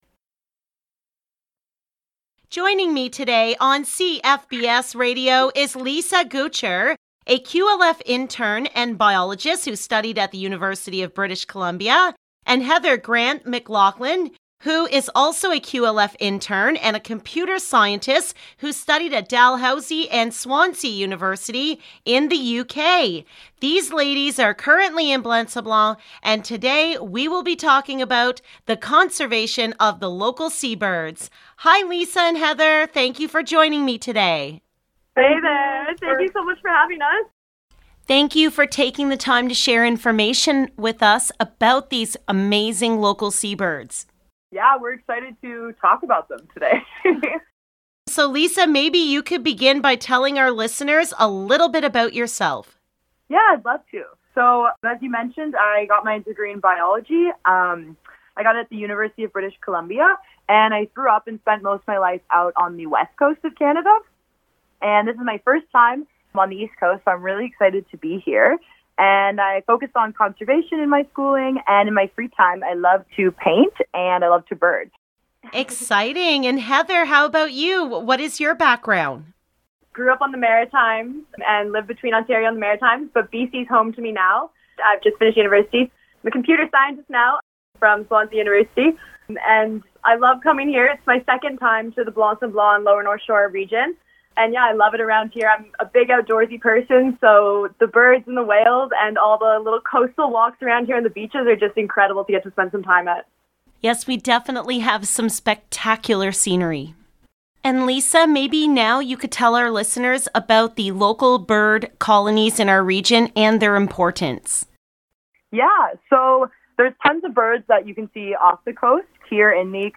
LOCAL NEWS - AUGUST 3, 2021 - Learning about our local seabird population